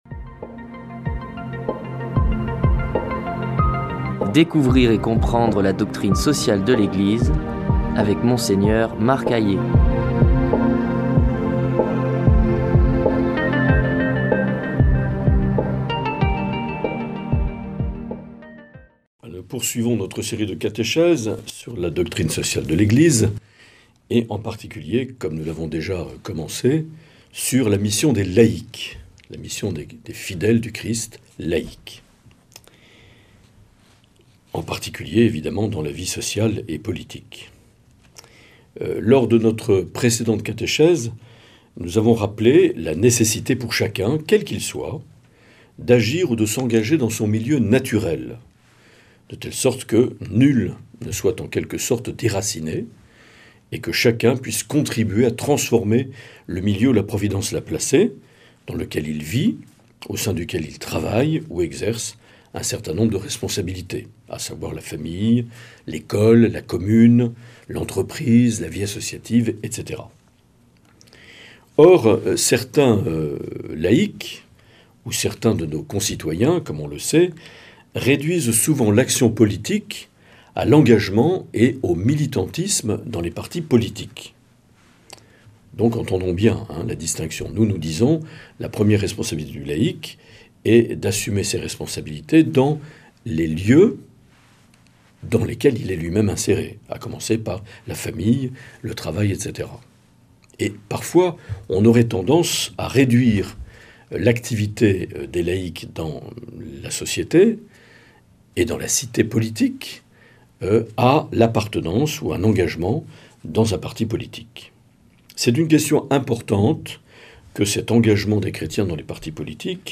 Une émission présentée par
Monseigneur Marc Aillet
Présentateur(trice)